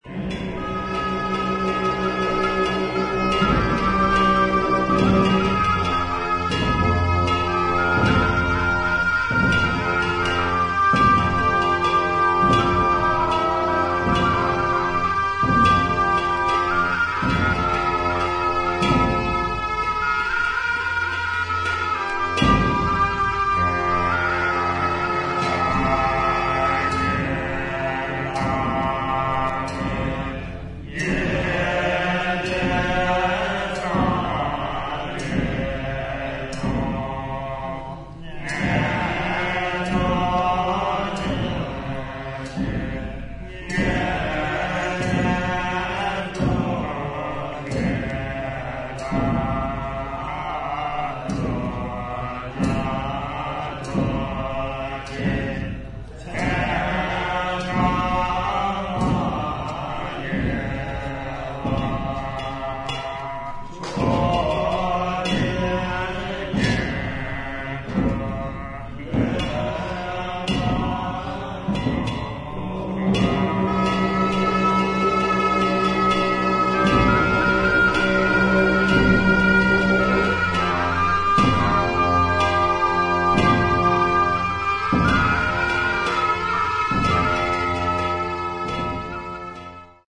本作は、ブータンの伝統的な宗派「ドゥプカ派」の儀式に焦点を当て、総勢76人のラマ僧と僧侶が神聖な寺院で長いトランペット、ショーム、シンバル、太鼓などのチベット楽器を用いて詠唱し、演奏。臨場感あふれる声明や打楽器の演奏、メディテーティブな僧院音楽が生々しく記録された大変貴重な音源です。